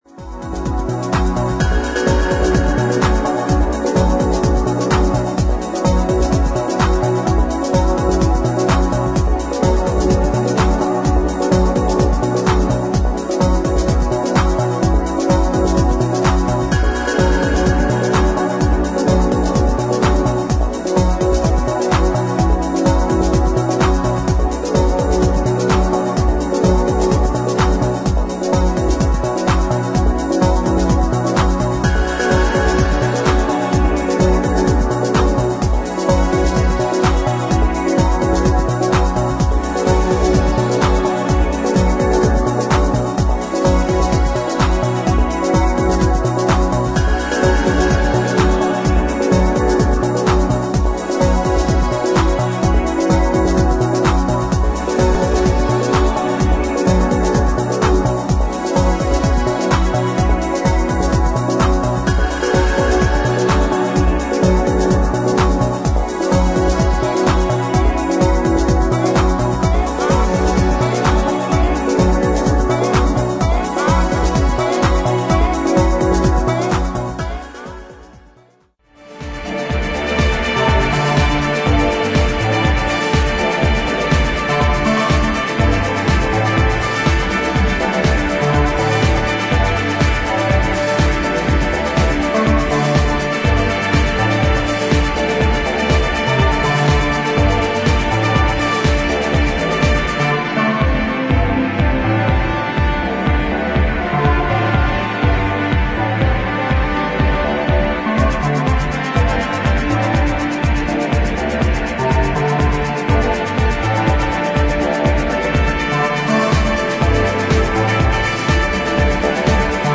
テクノとディープ・ハウスを絶妙なバランスでブレンドした全3トラックを収録。